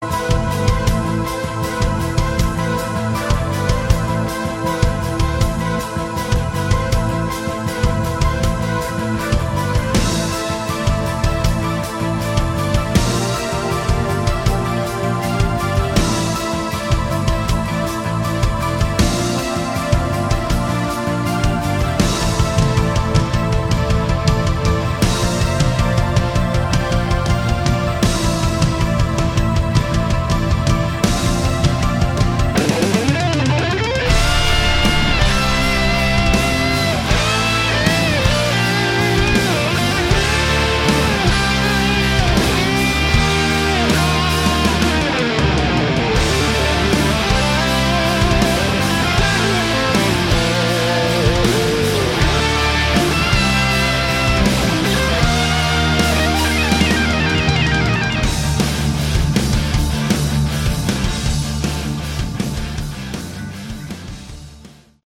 Category: Melodic Rock
drums
bass
guitars, keyboards
vocals